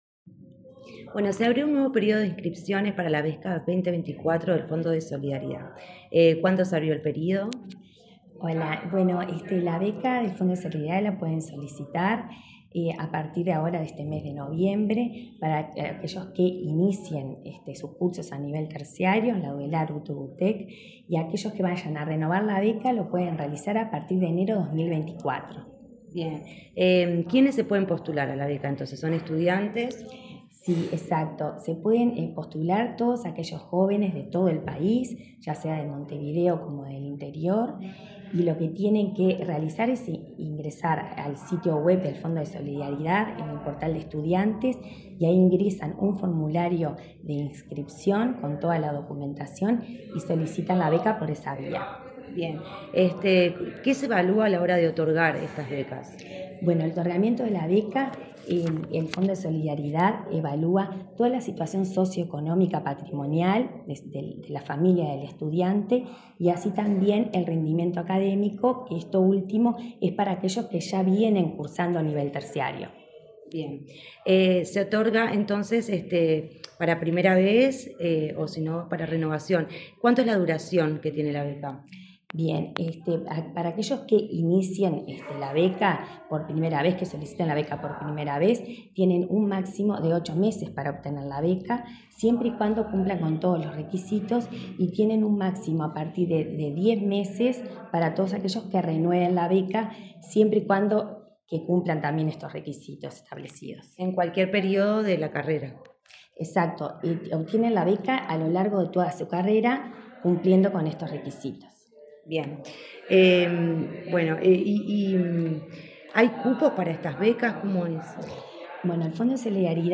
Entrevista a la presidenta del Fondo de Solidaridad, Rosario Cerviño